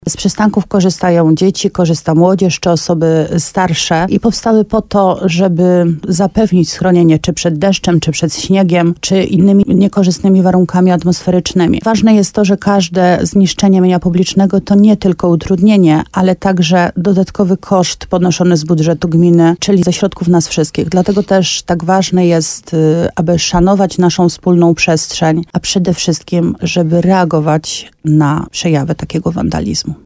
– Całe gminne mienie, w tym też przystanki, to jest nasze wspólne dobro – mówi wójt gminy Łabowa Marta Słaby i apleuje o reagowanie na wszelkie oznaki wandalizmu.